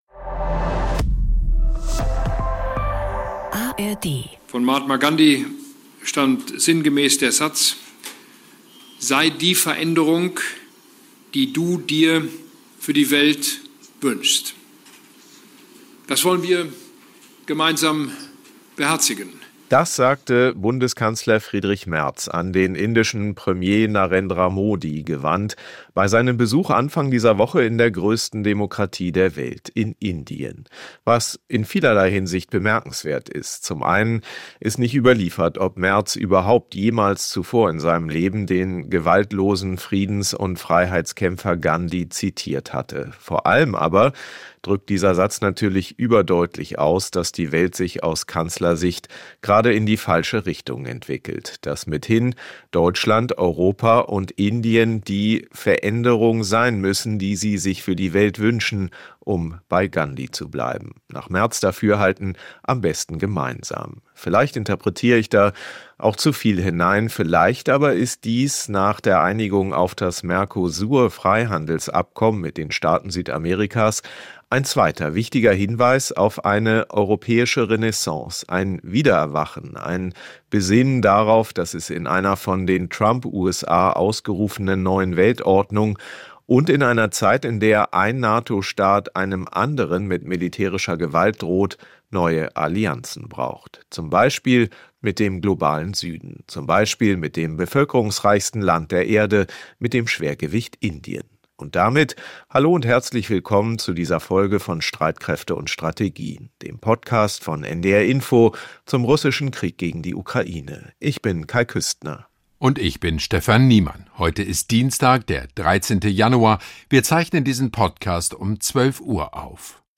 Der Sicherheitsexperte schaut im Interview auch auf die Ukraine.